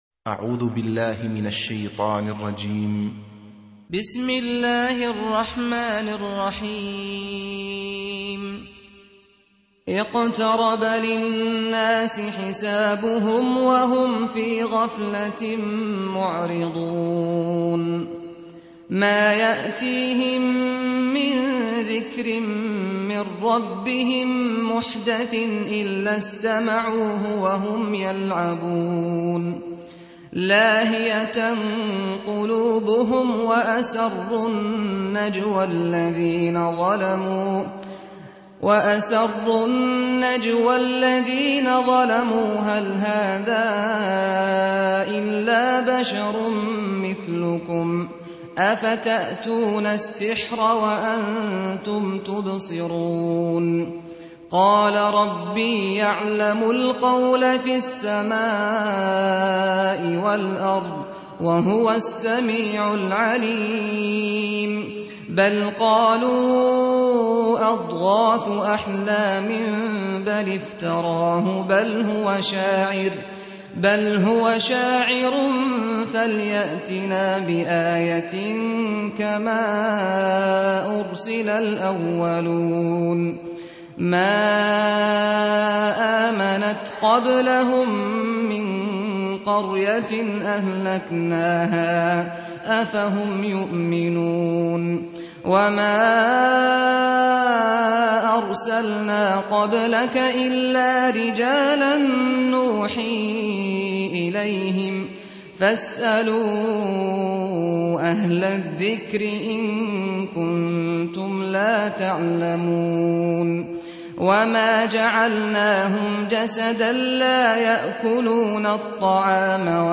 Surah Repeating تكرار السورة Download Surah حمّل السورة Reciting Murattalah Audio for 21. Surah Al-Anbiy�' سورة الأنبياء N.B *Surah Includes Al-Basmalah Reciters Sequents تتابع التلاوات Reciters Repeats تكرار التلاوات